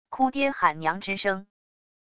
哭爹喊娘之声wav音频